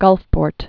(gŭlfpôrt)